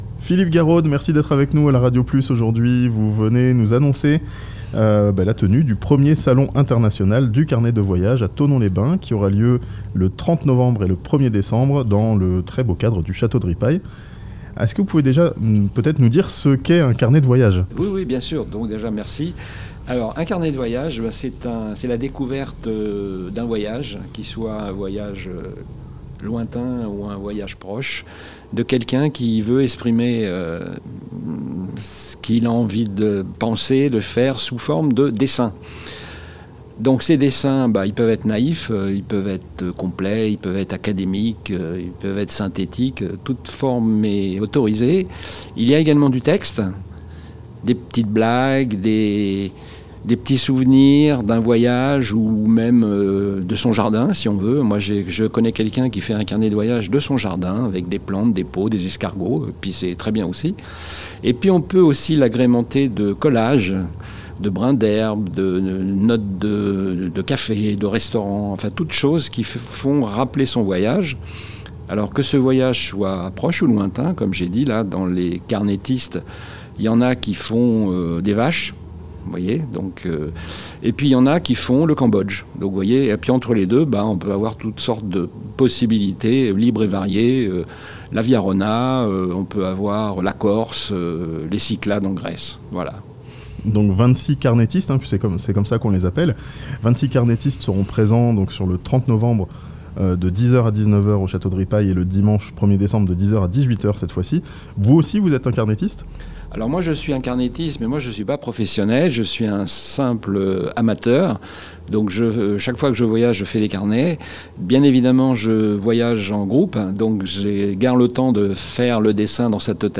1er salon international des carnets de voyage, ce weekend à Thonon (interview)